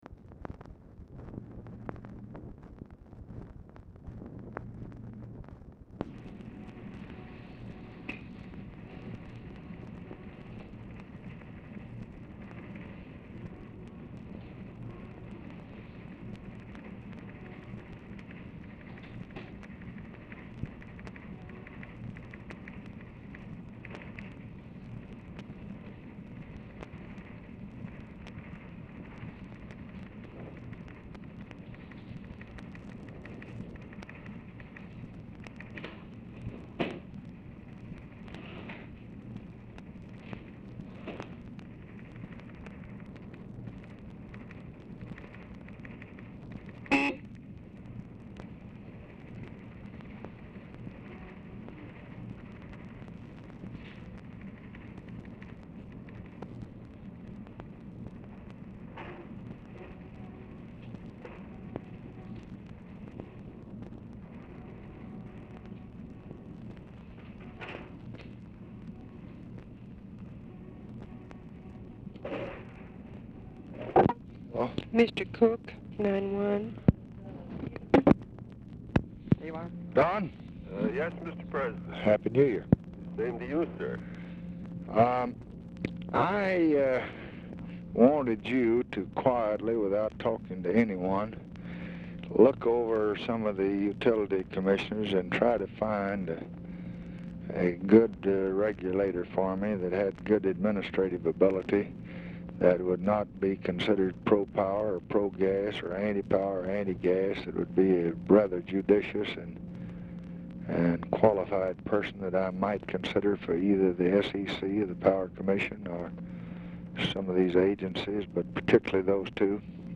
Telephone conversation
TELETYPE AUDIBLE IN BACKGROUND
Dictation belt